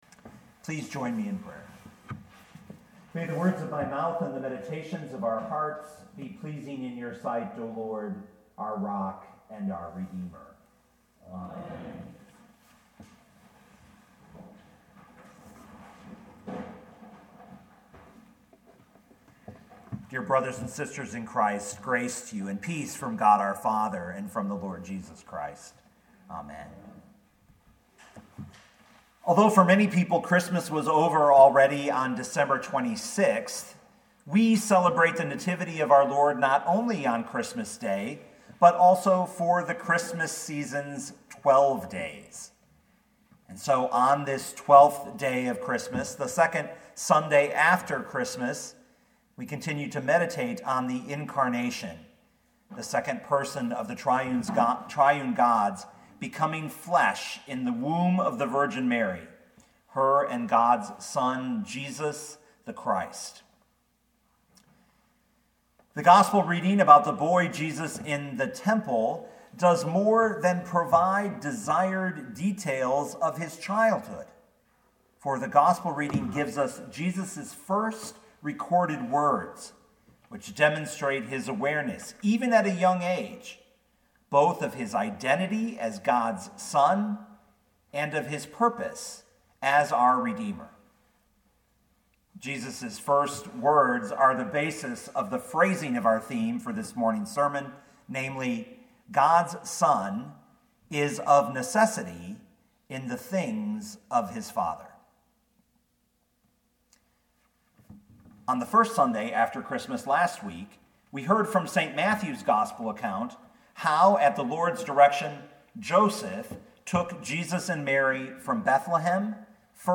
2020 Luke 2:40-52 Listen to the sermon with the player below, or, download the audio.